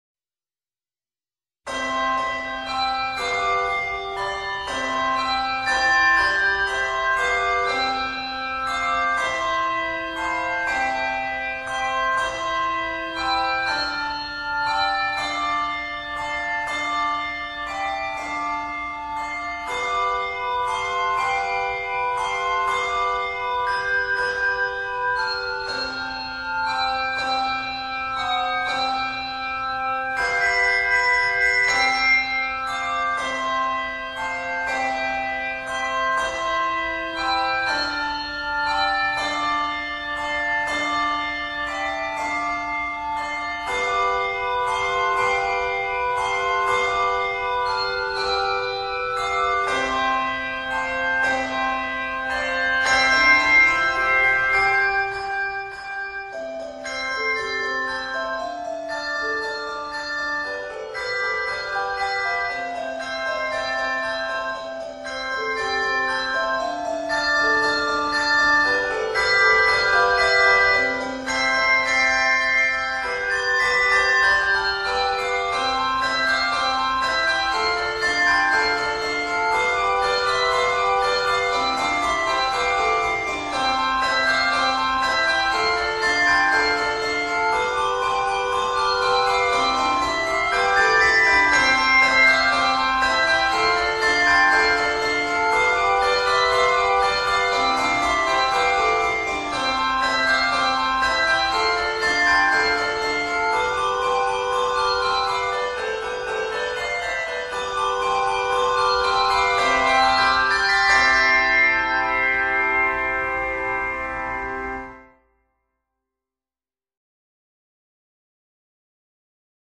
Set in C Major, it is 63 measures.